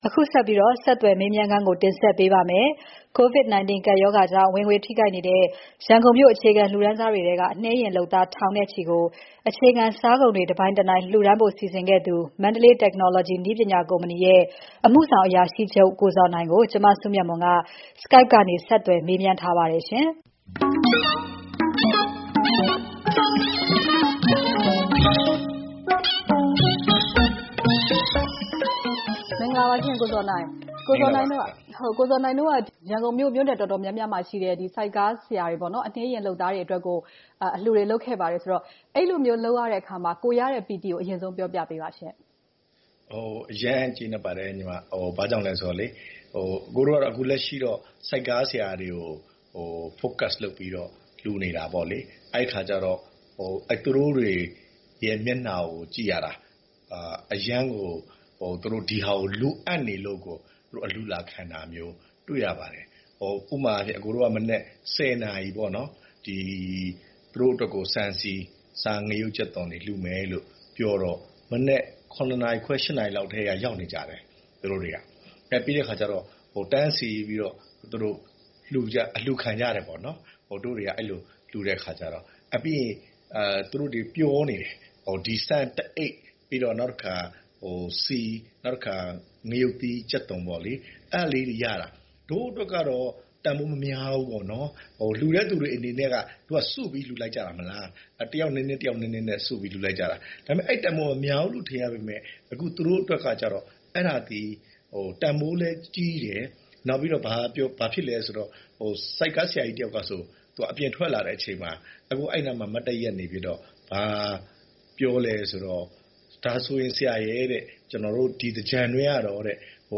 Skype မှတဆင့် ဆက်သွယ်မေးမြန်